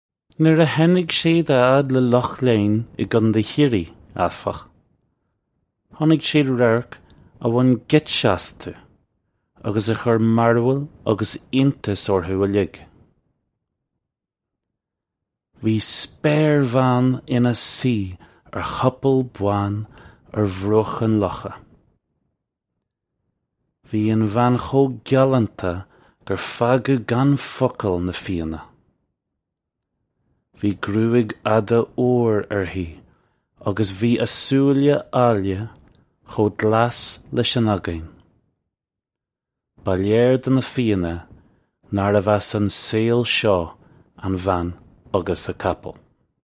This comes straight from our Bitesize Irish online course of Bitesize lessons.